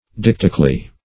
Search Result for " deictically" : The Collaborative International Dictionary of English v.0.48: Deictically \Deic"tic*al*ly\, adv.